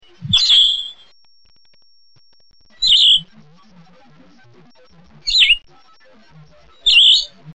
Solitary Vireo (Vireo solitarius)
b. Song: short whistled phrases, with rising and falling inflection, rendered with a short wait between phrases (P).
Often 2 or 3 notes per phrase (R).
Slow, slurred, rising and falling phrases, with a buzzy quality: zoowee, zeeyoo, zoowee, etc., as if asking a question, then answering; scratchy, but not very hoarse.